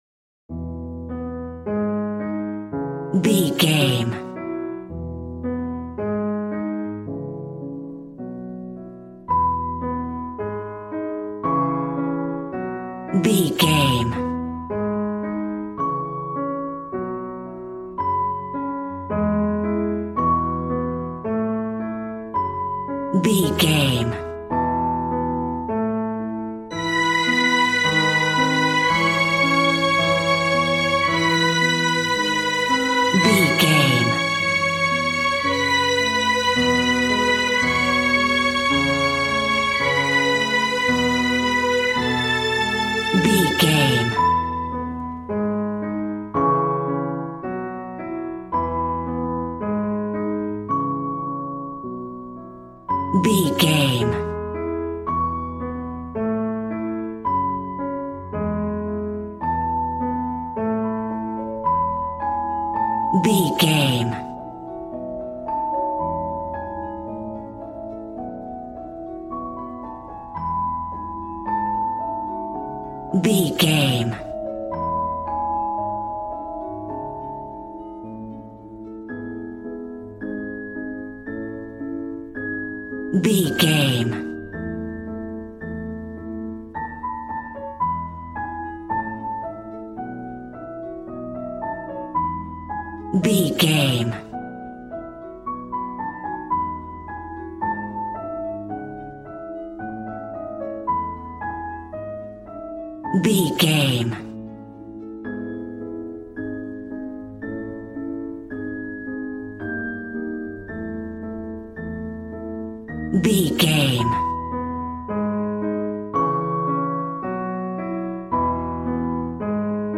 Regal and romantic, a classy piece of classical music.
Ionian/Major
regal
strings
violin